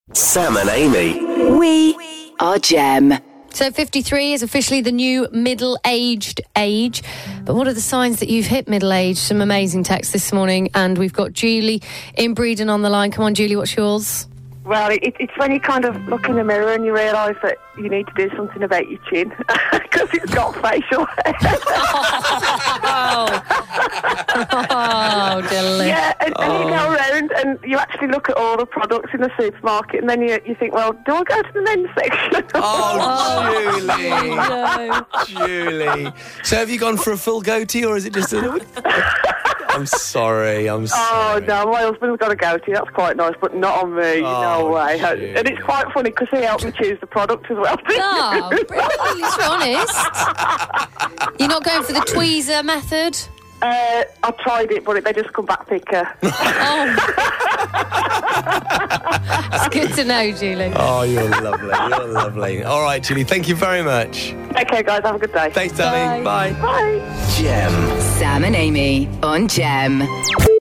called the show this morning to tell us about how she realised she was actually middle aged now...